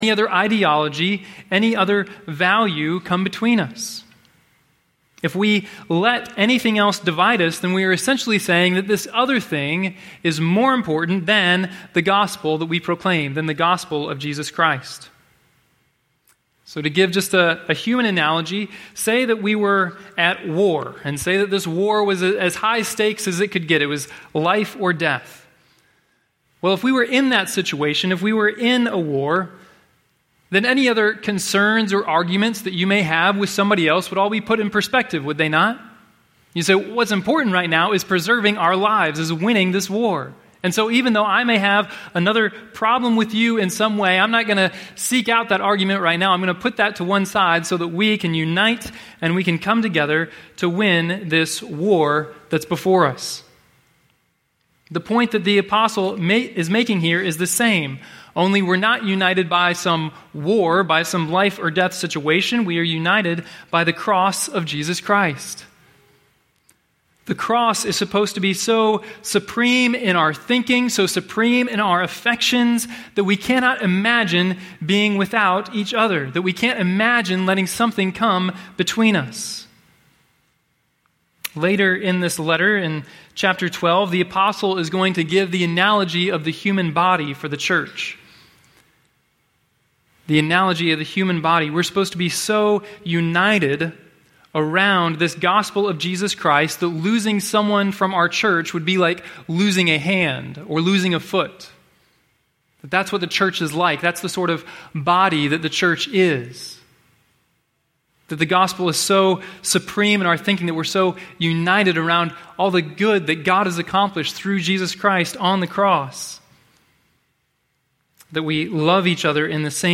Note: due to technical problems the first few minutes of this sermon is missing.